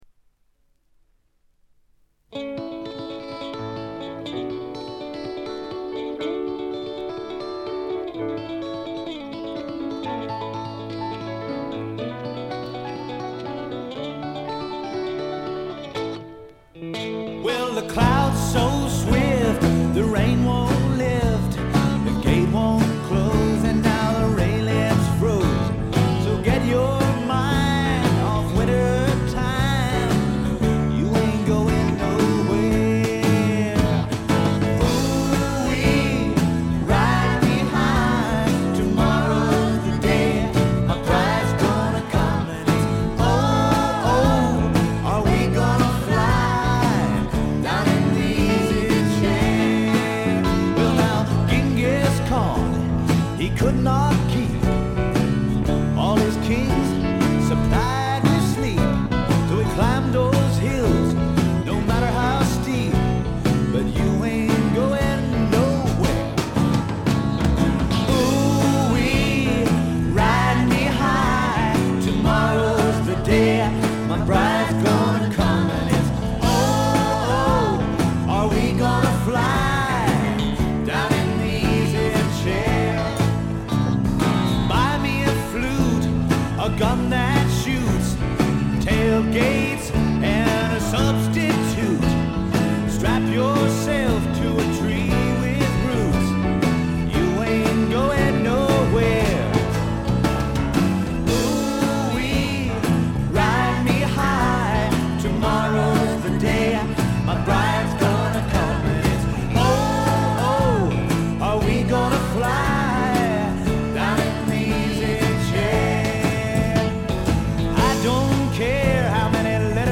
英国フォークロックの基本。
試聴曲は現品からの取り込み音源です。